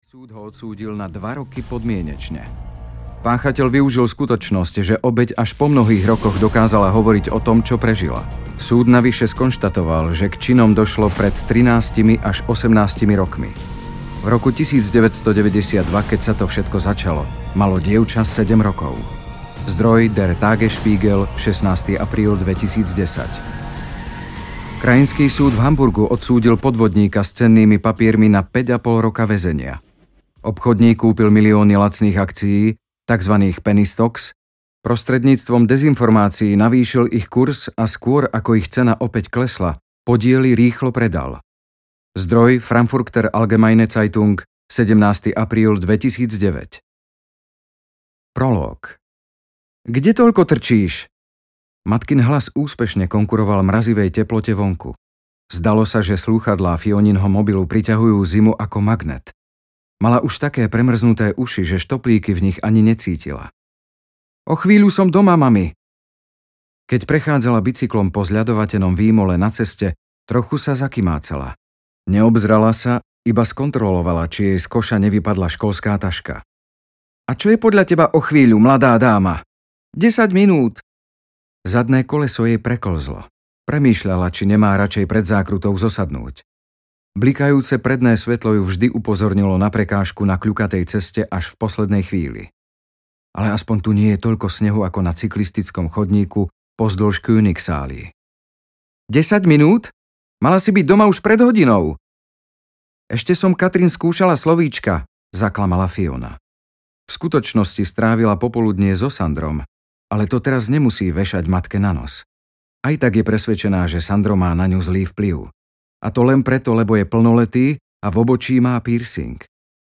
Izolovaní audiokniha
Ukázka z knihy